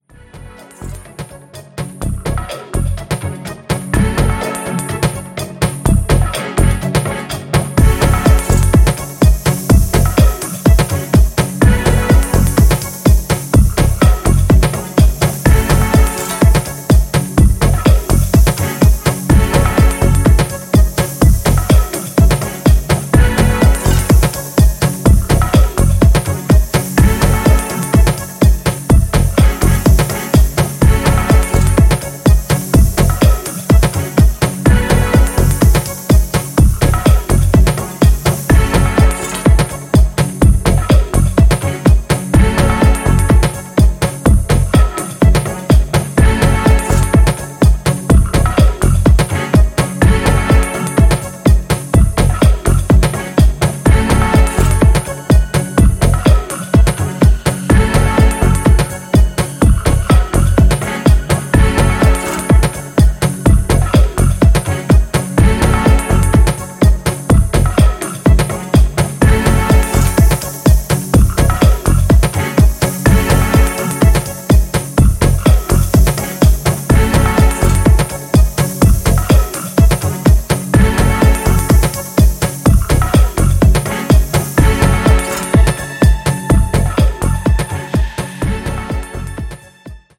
deep roller